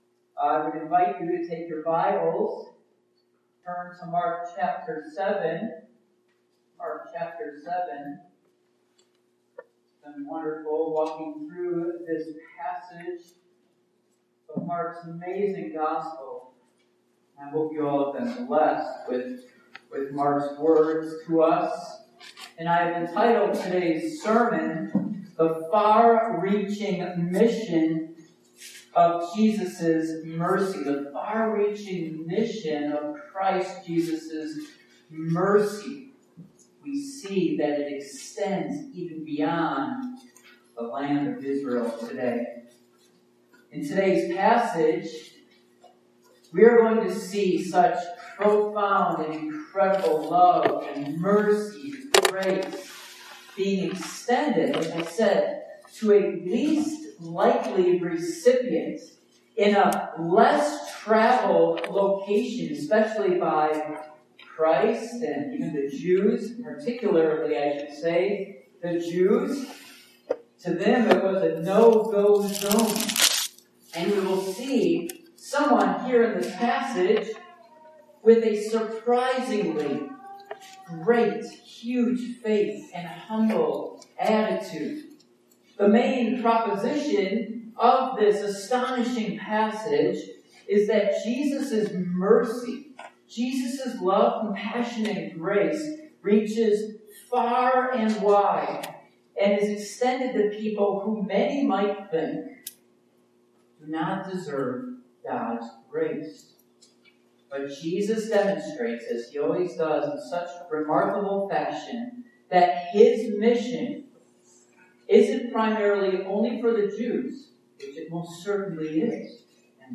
Passage: Mark 7:24-30 Service Type: Morning Worship